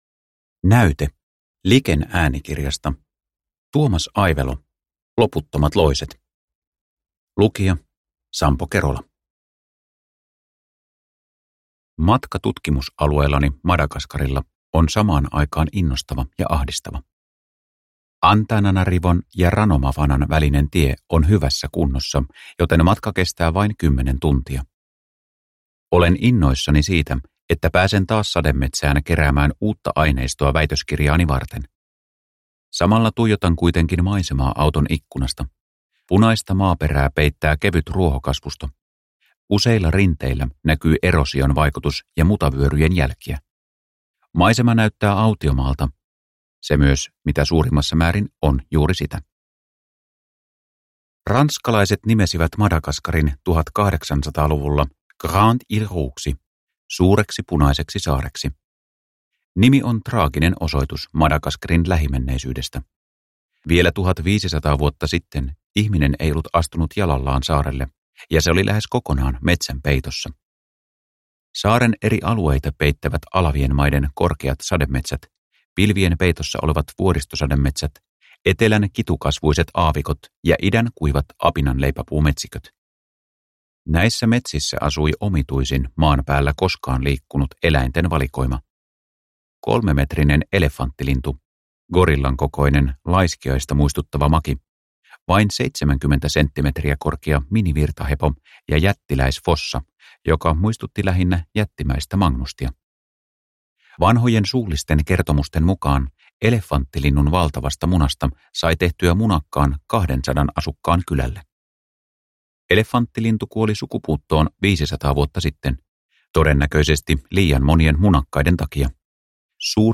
Loputtomat loiset – Ljudbok – Laddas ner